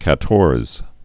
(kă-tôrz)